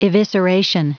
Prononciation du mot evisceration en anglais (fichier audio)
Prononciation du mot : evisceration